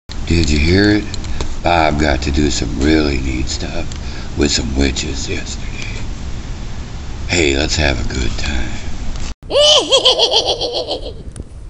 ----------- I Always Had A Timed Voice For Jack Nickolson But Not With The Sound Made As He Makes It -----------